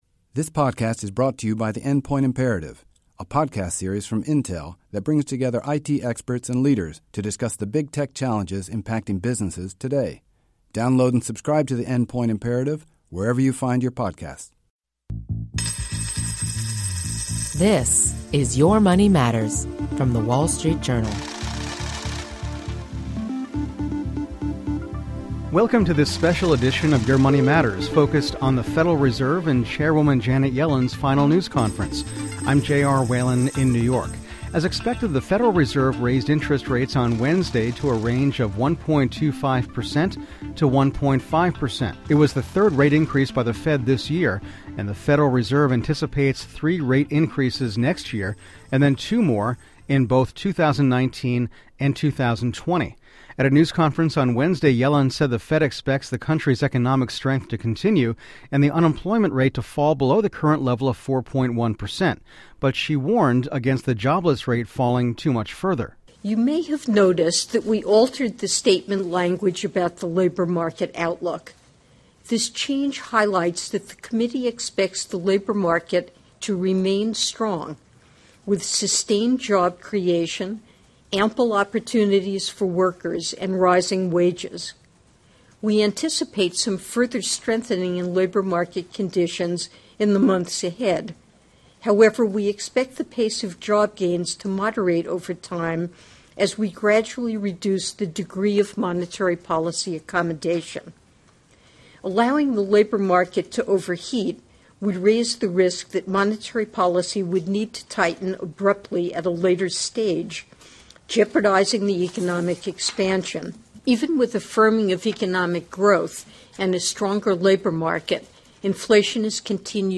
Special Fed Coverage: Yellen Press Conference